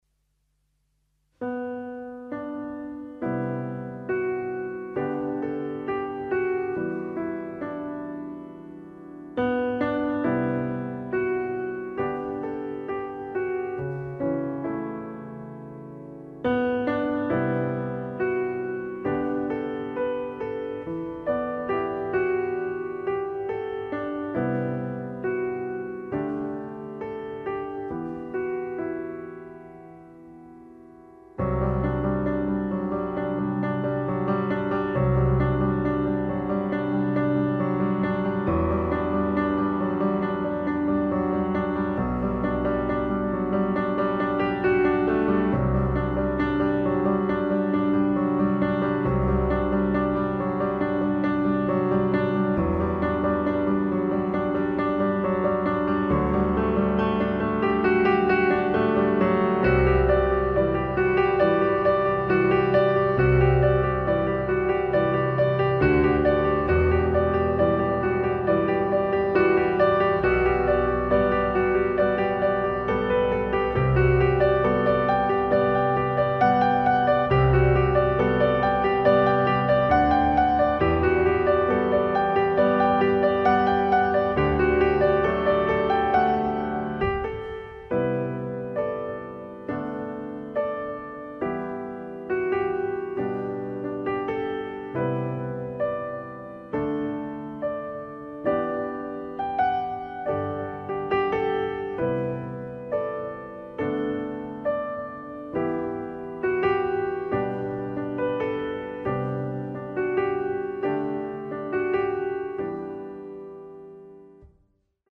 SYN项目官网的主题曲.